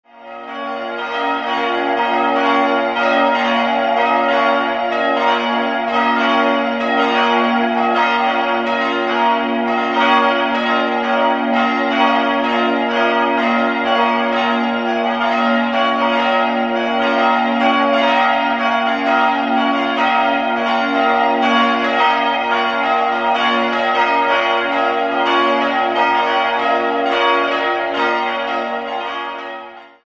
Die drei Schilling-Glocken sind in progressiver Rippe gegossen.